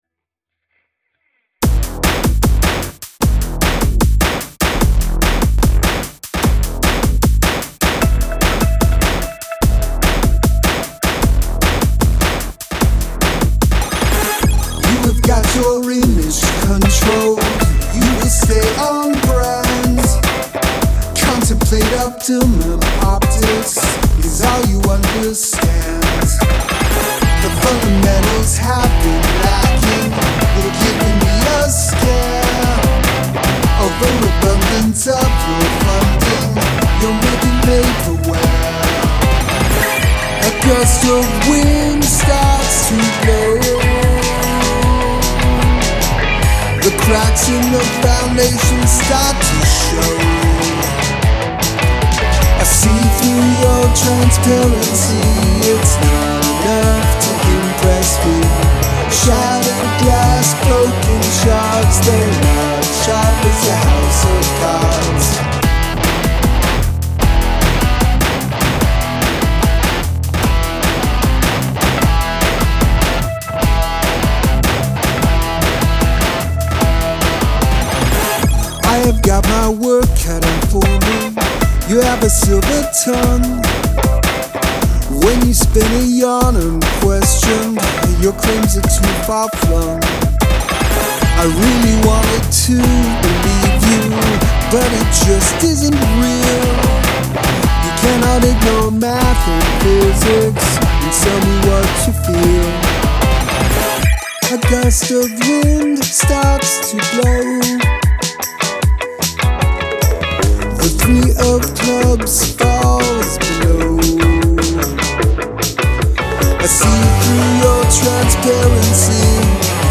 I love the herky-jerky verses.
right away i love the music. Heavy and interesting.
Dark ominous vibe in this, I like the music.